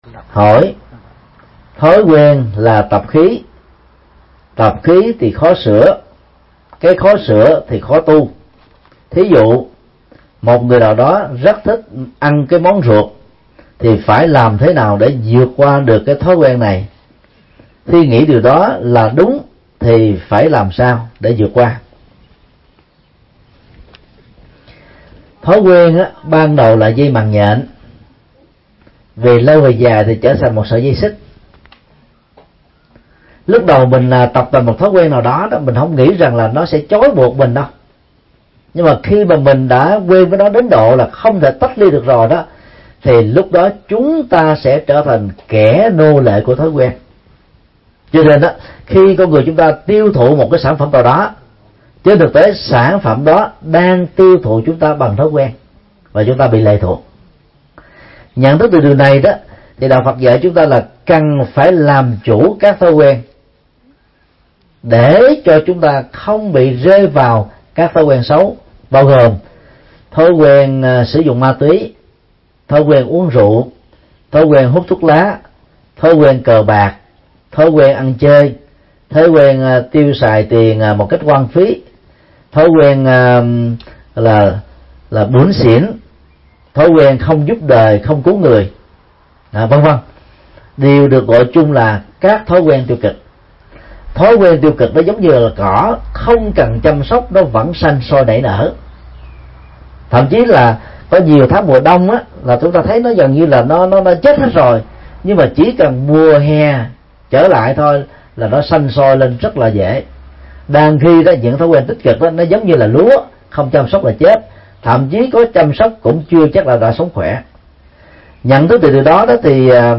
Vấn đáp: Vượt qua thói quen